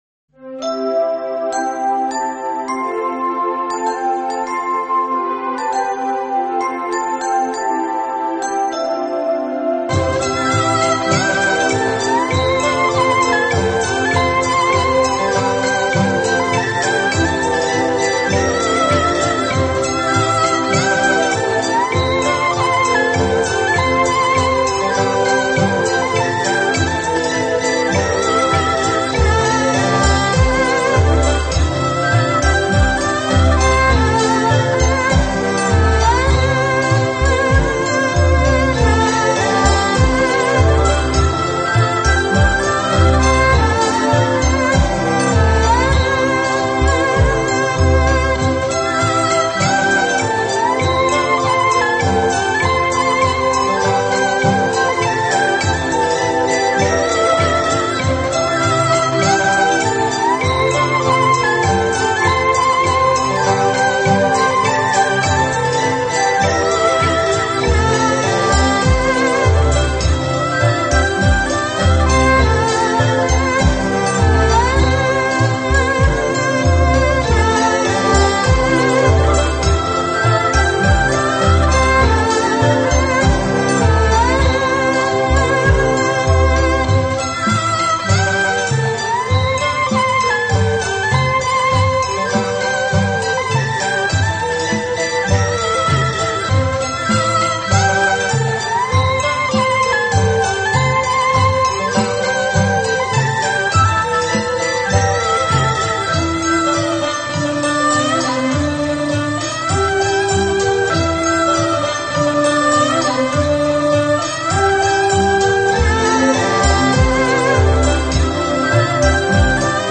迷雾古城罗声乍响 千年穹音风华再现 [锣与弦的迷舞]